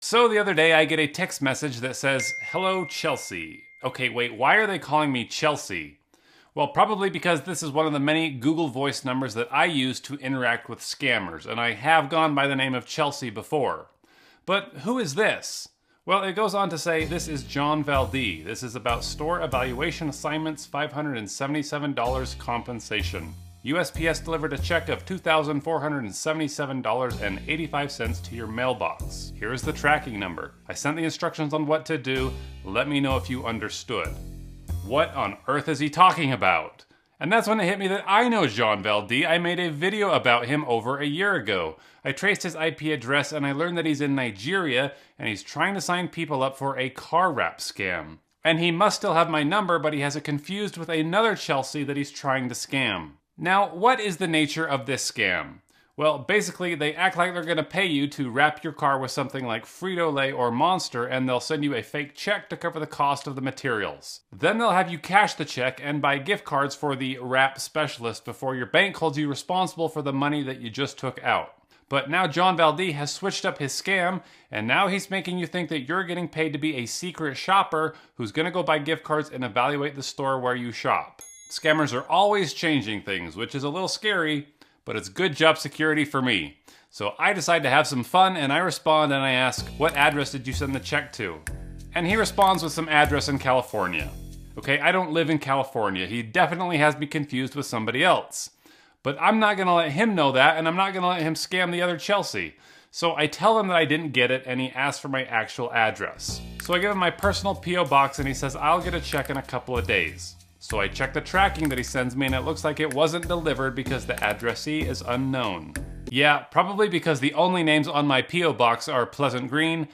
Call In Radio Show